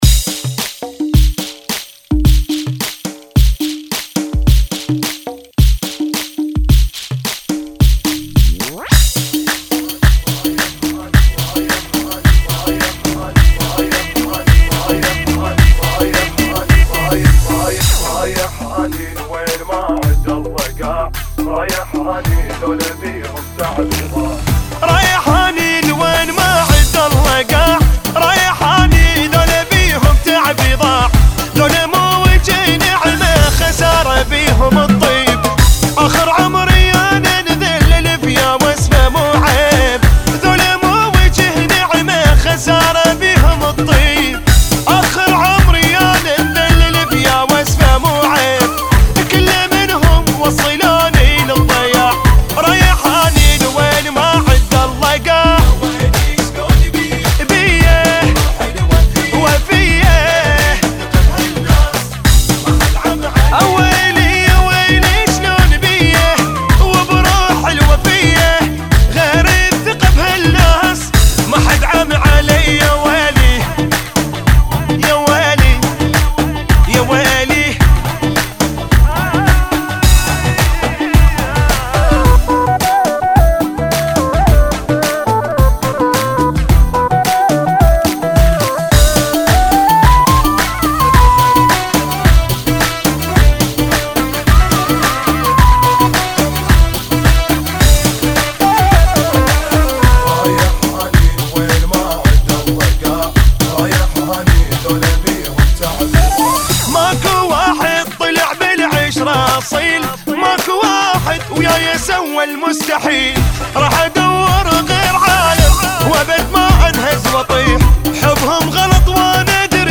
108 Bpm